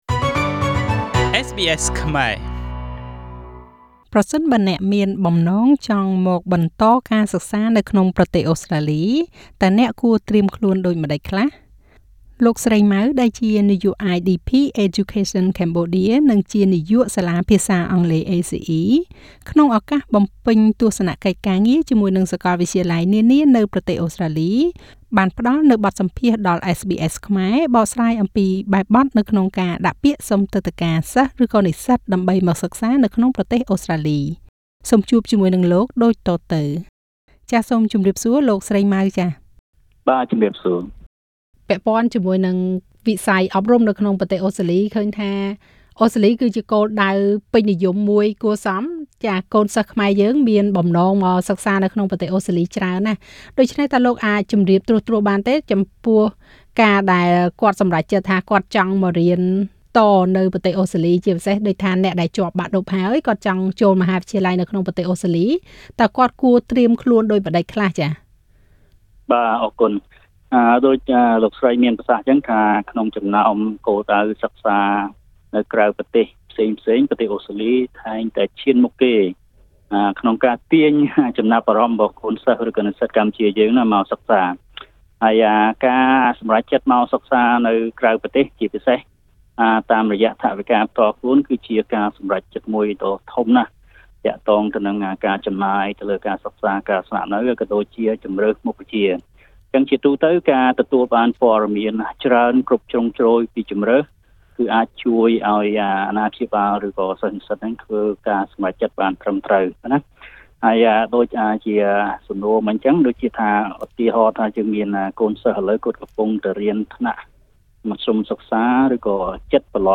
បានផ្តល់បទសម្ភាសន៍ដល់ SBS ខ្មែរ